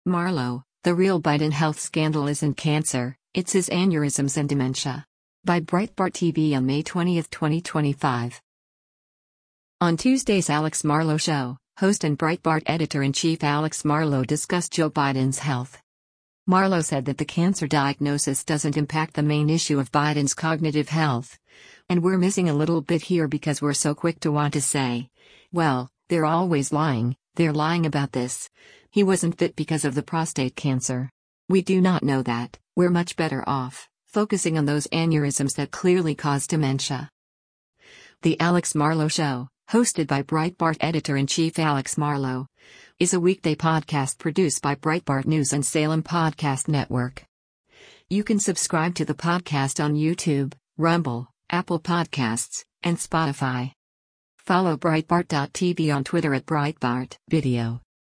On Tuesday’s “Alex Marlow Show,” host and Breitbart Editor-in-Chief Alex Marlow discussed Joe Biden’s health.